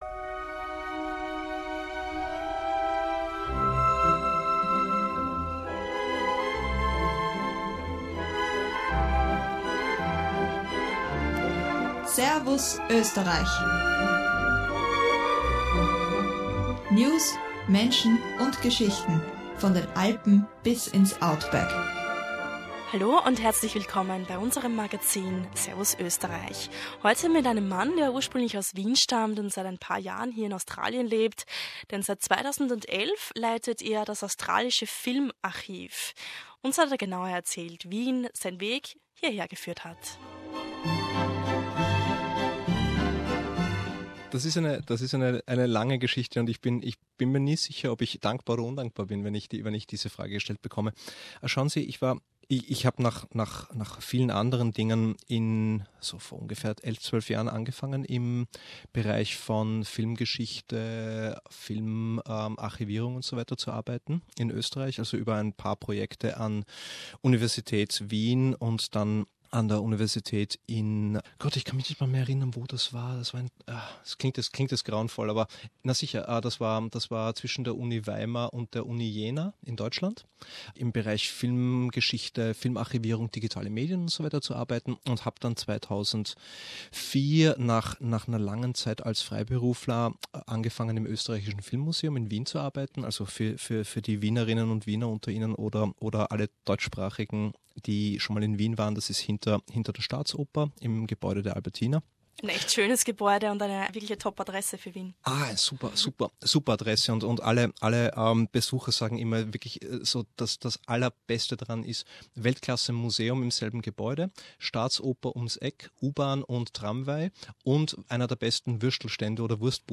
Der Experte im Bereich der audiovisuellen Archivierung hat uns einen Besuch im Studio abgestattet.